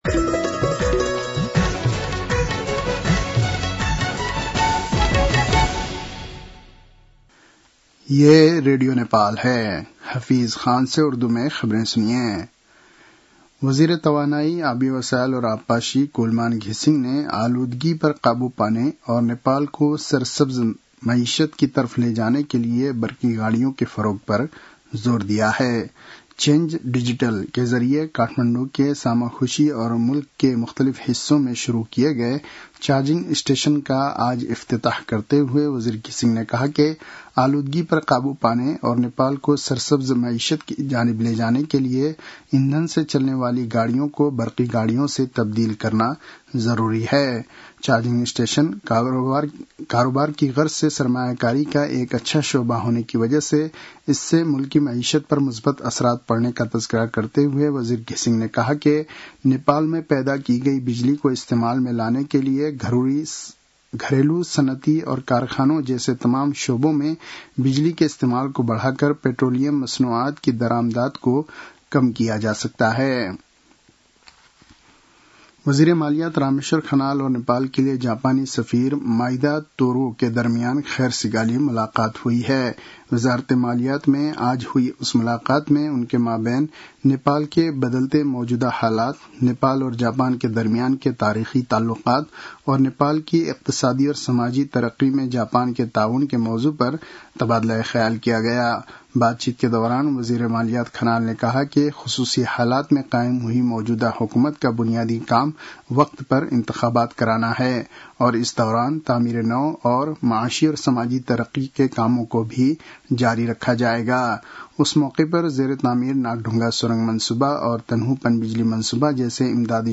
उर्दु भाषामा समाचार : १२ असोज , २०८२